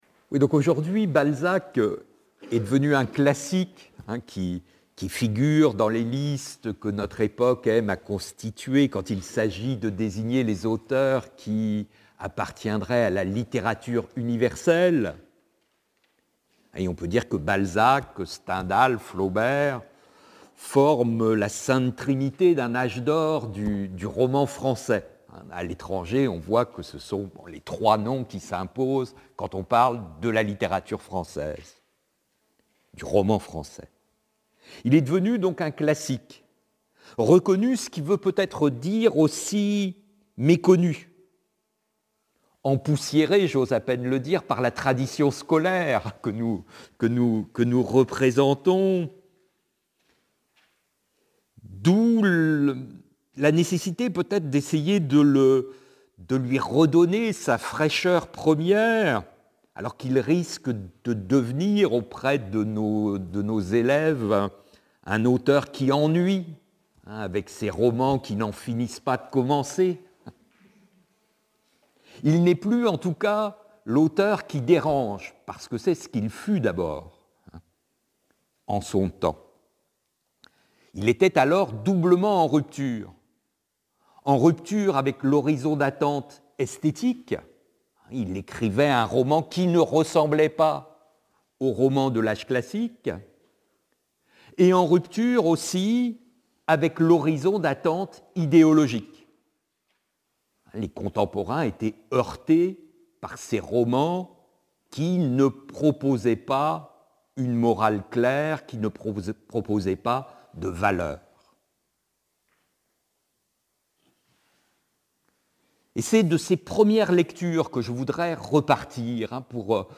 Première partie de la conférence (45 minutes)
premiere_partie_conference.mp3